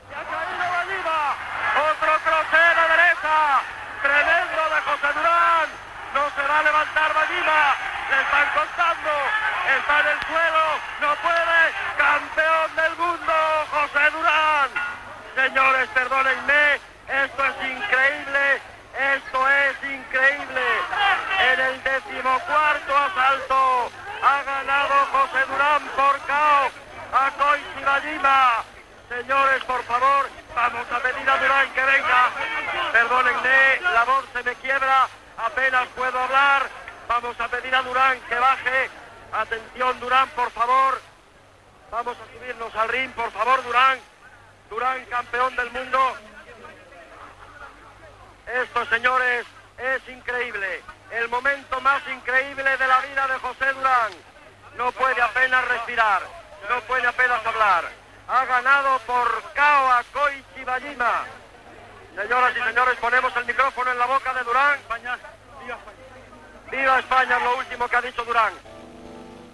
Transmissió de l'últim assalt del combat de boxa entre José Durán i el japonès Koichi Wajima, des de Tòquio.
Esportiu